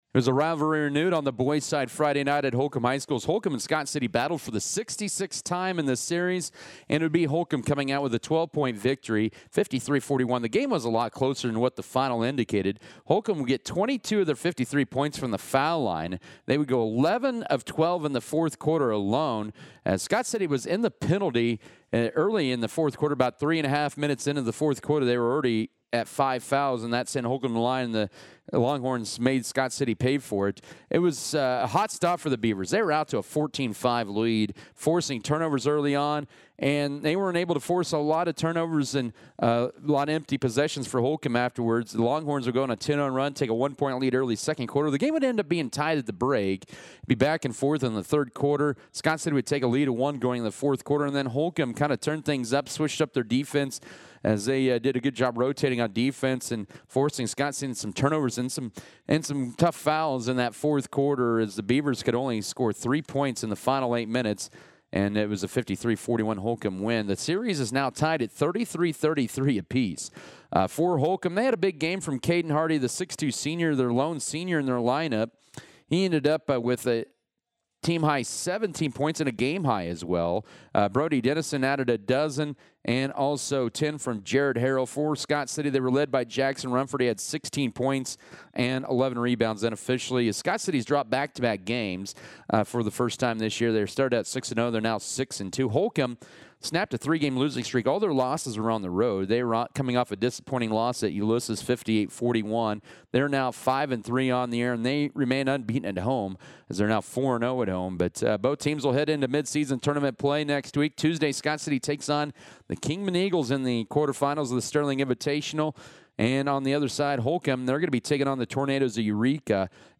Audio Game Recap